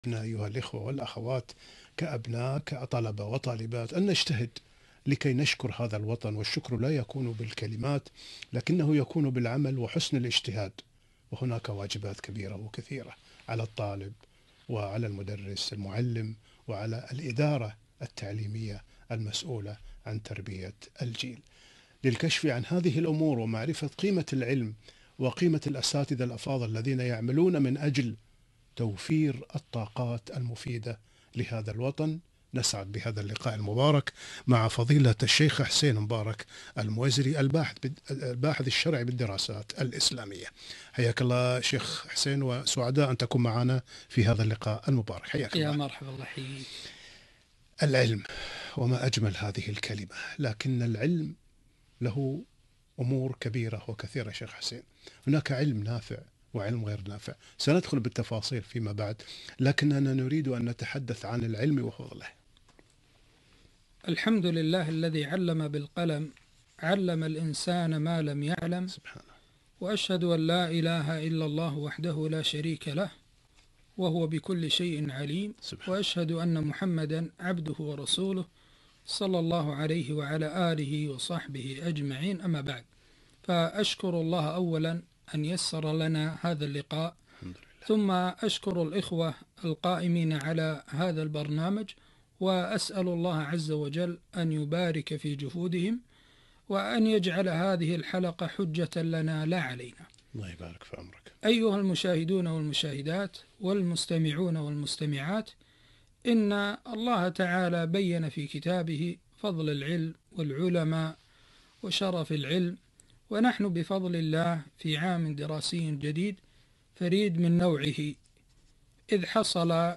وقفات مع بدء العام الدراسي الجديد - لقاء إذاعي